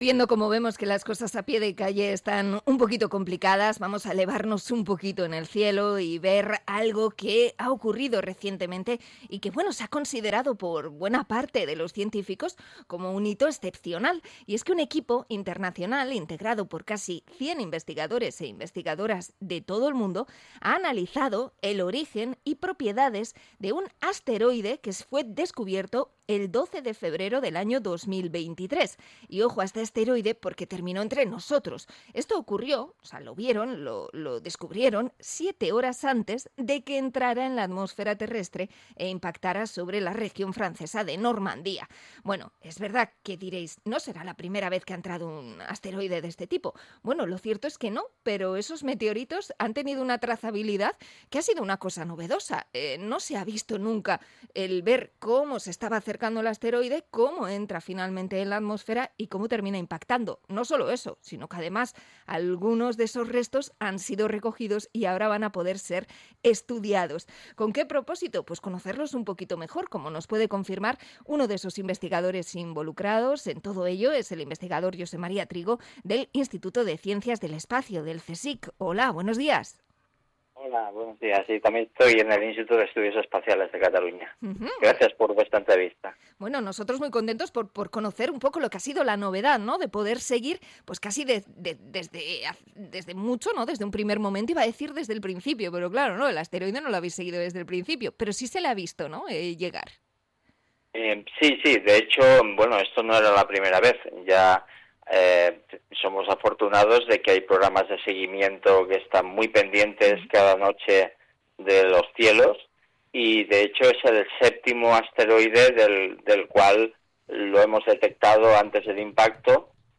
Entrevista a investigador del CSIC sobre un asteroide que ha entrado en la atmósfera